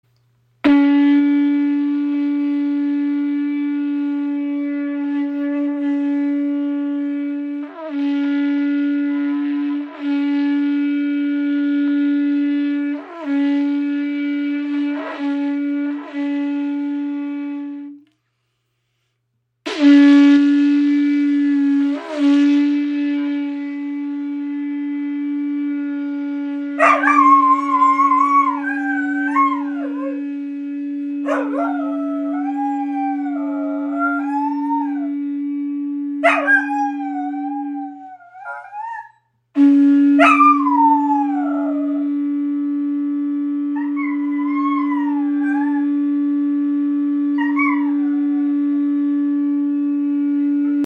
Kuhhorn, Signalhorn
Klangbeispiel
Dieses authentische Stück verkörpert die tiefe Verbundenheit mit der Vergangenheit und bringt einen kräftigen, durchdringenden und tragenden Klang hervor.